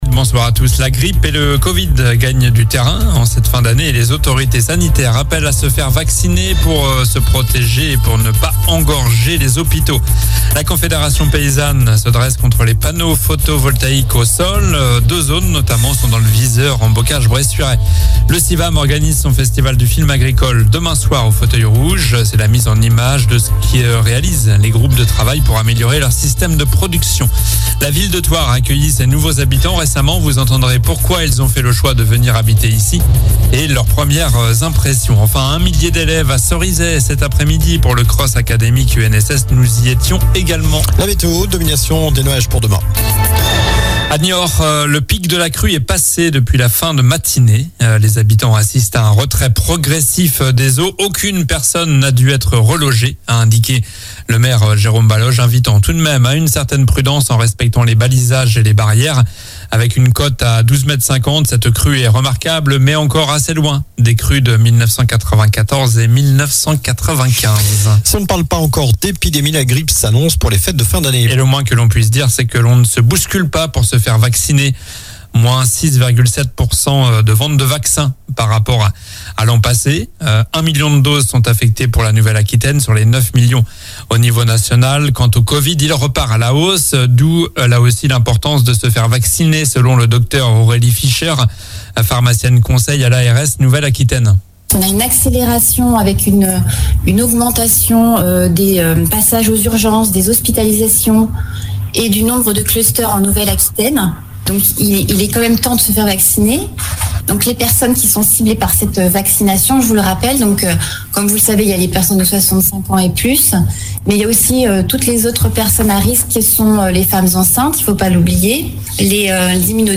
Journal du mercredi 13 decembre (soir)